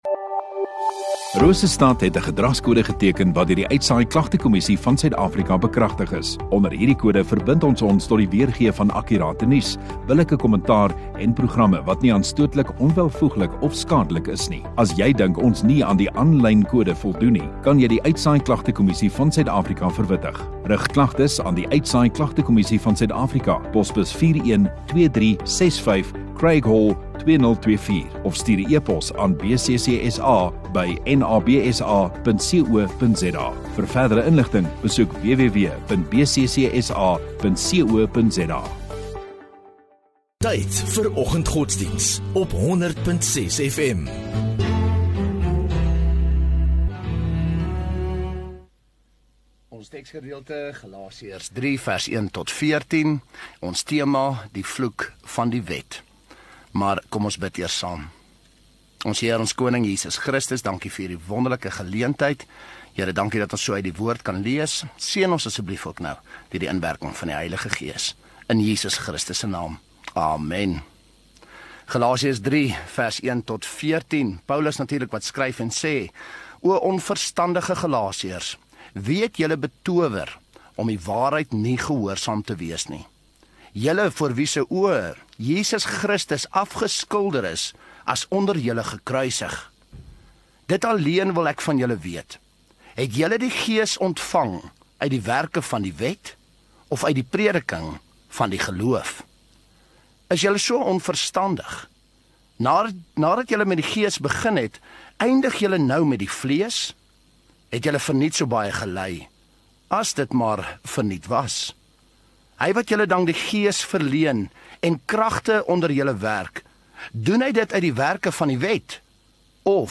18 Mar Dinsdag Oggenddiens